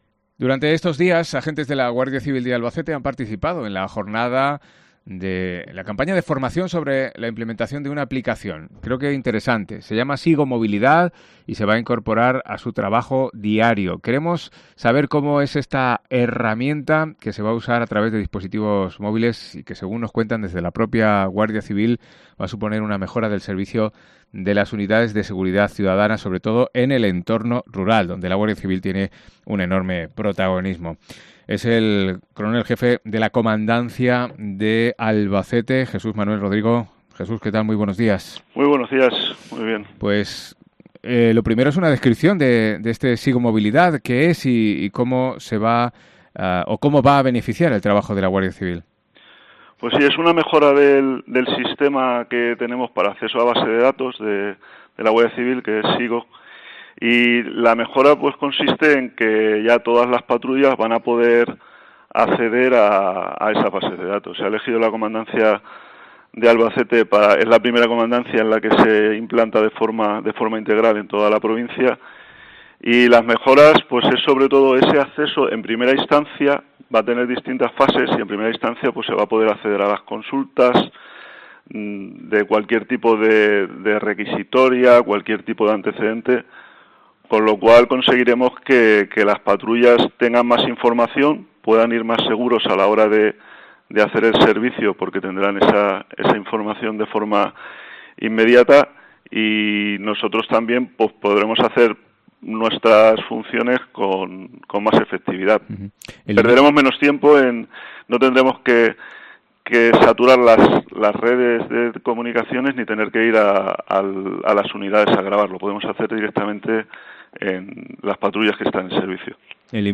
ENTREVISTA
Jesús Manuel Rodrigo, Jefe de la Comandancia de la Guardia Civil de Albacete